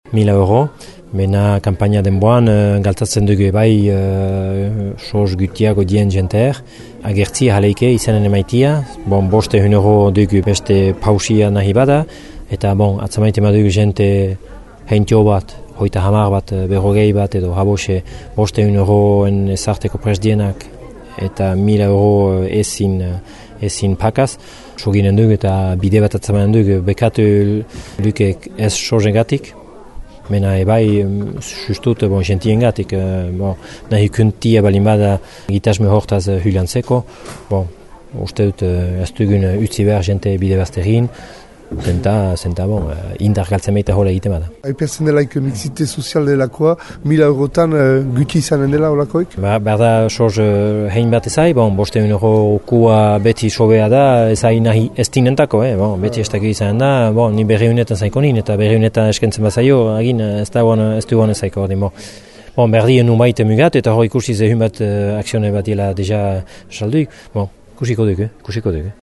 Akzioen prezioaz entzün Mixel Etxebest Mauleko aüzapezak erran deigüna :